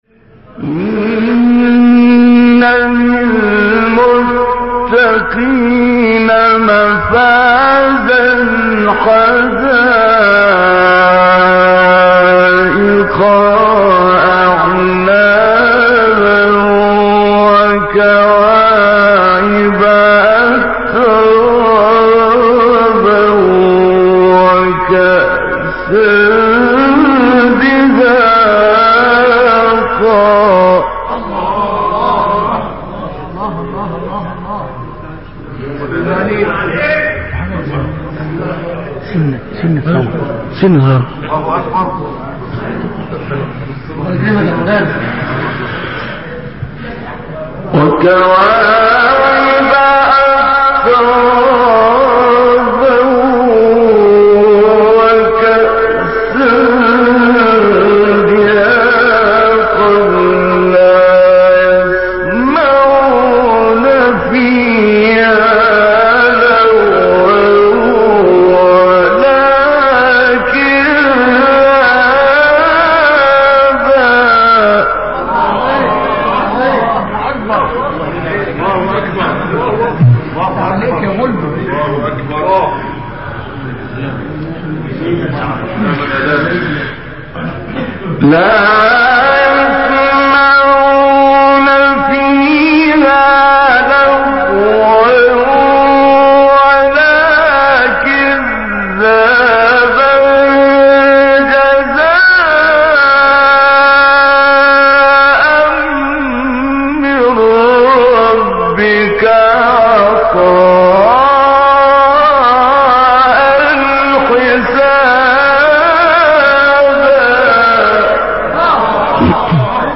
تلاوت بخشی از سوره نبا با صدای استاد طنطاوی | نغمات قرآن | دانلود تلاوت قرآن